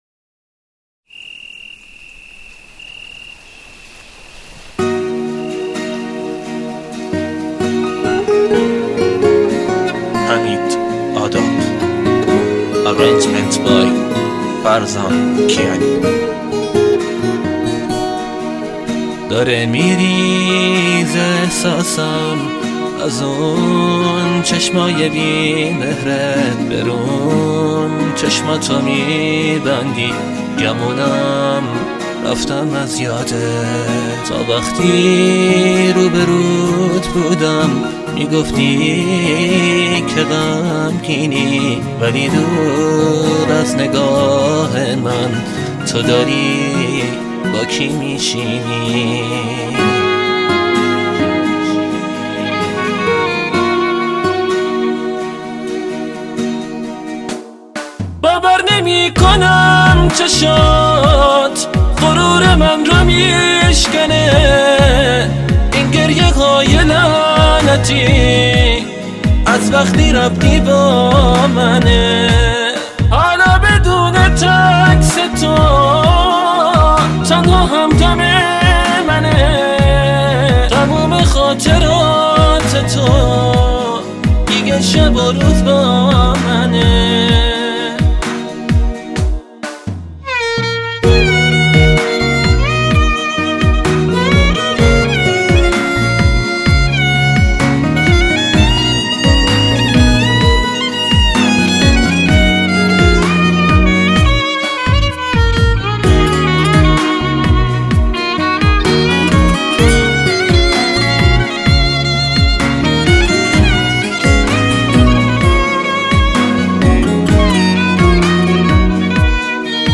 سه تار و گیتار و الکتریک و اکوستیک